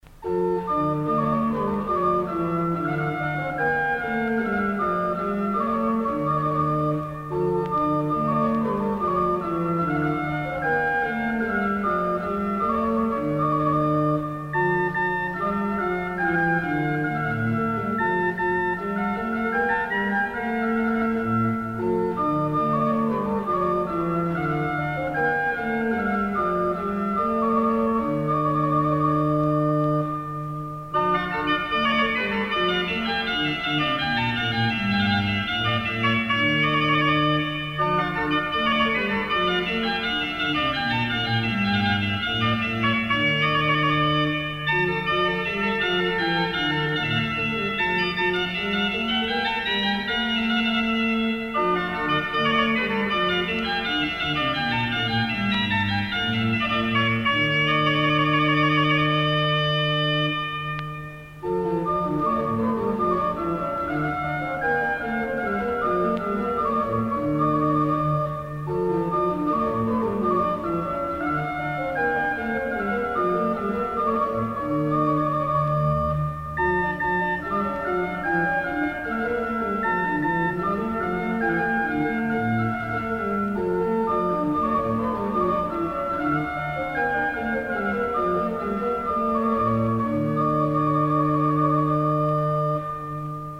Écoutez ICI, pour vous recueillir, René Saorgin interprétant un Noël de Balbastre, enregistré à Tende...